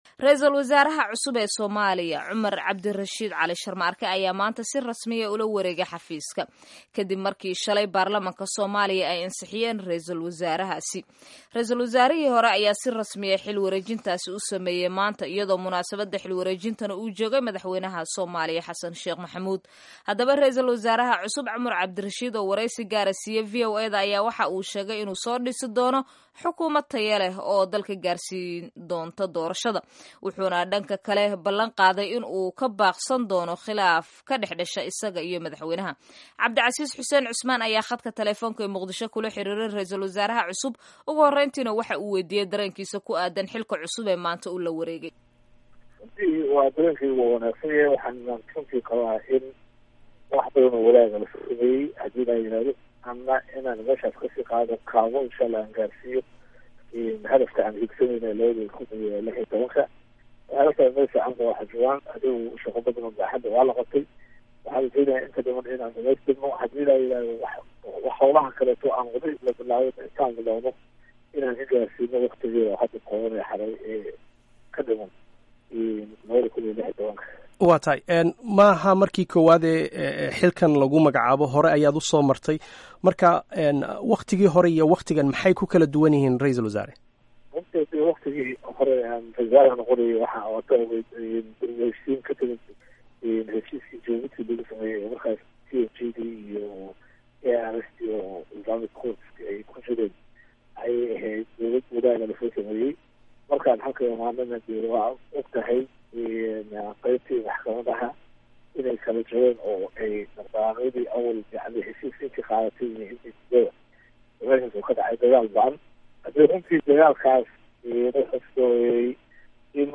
Dhageyso wareysiga RW cusub Sharmaarke.